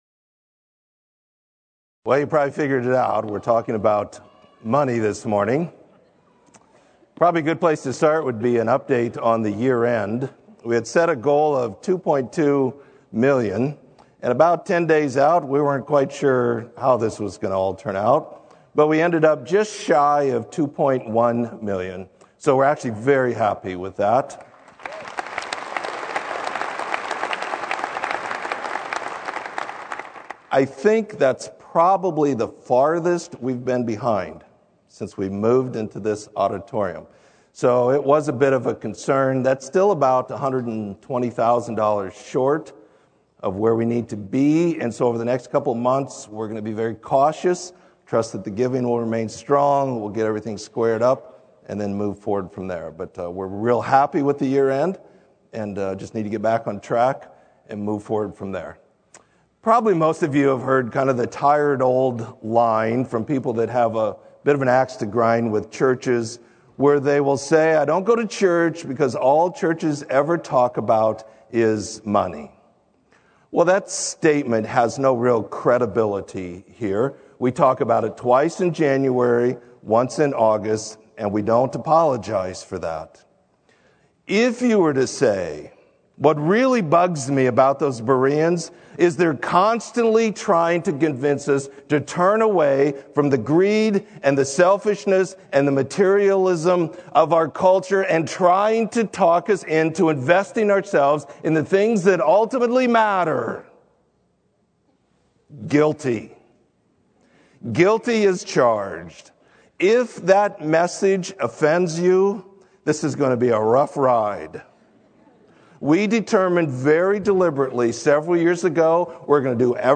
Sermon - Lincoln Berean